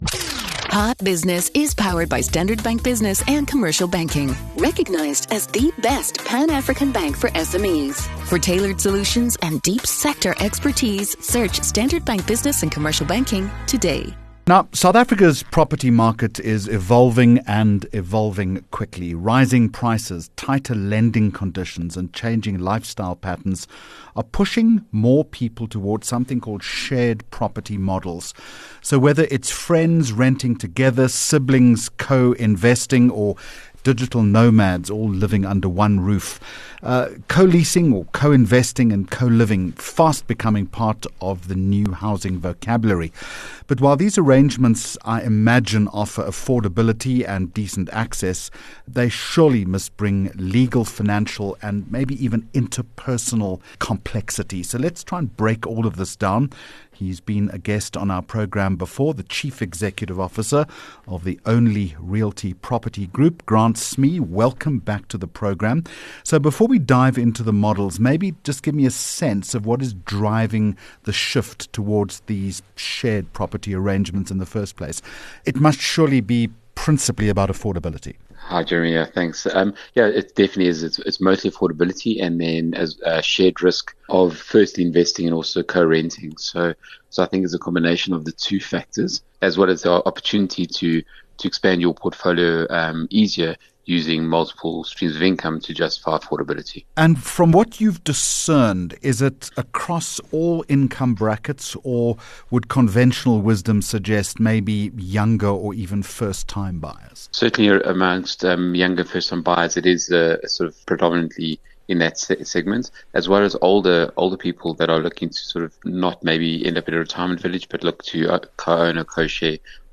22 Jul Hot Business Interview